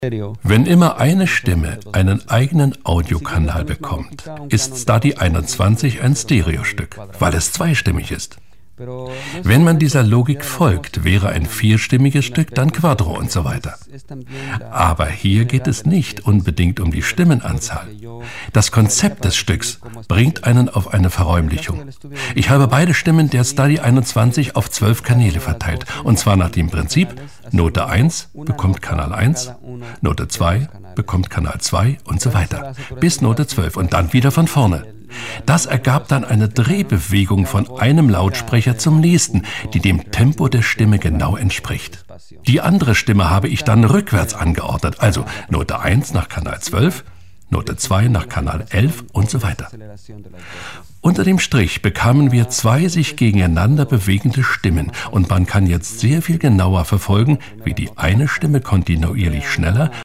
tiefe Stimme=WERBUNG:Bier,Bundeswehr, Ergo,Stadt München-DRAMA:Hörbuch, Kirchenlyrik-DOK:Jüdi. Museum-Leitstimme-COMIC:Paradiso-
Sprechprobe: eLearning (Muttersprache):